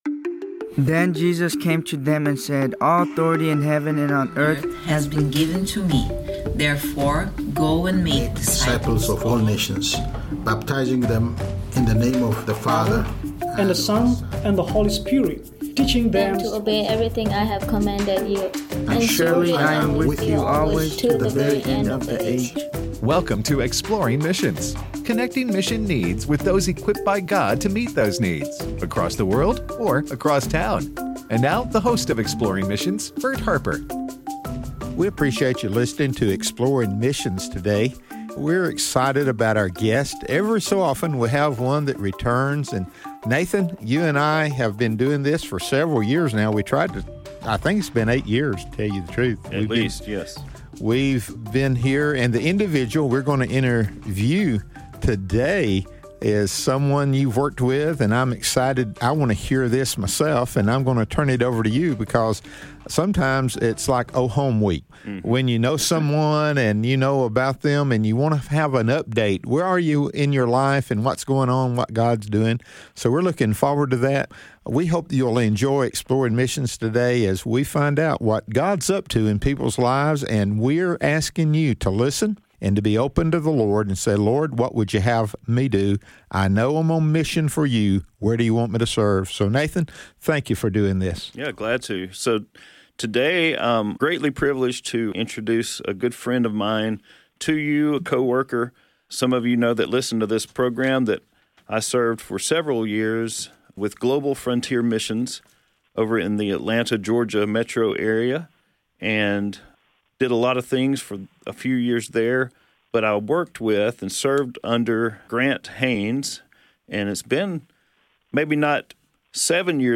Training and Preparing to Reach the Lost: A Conversation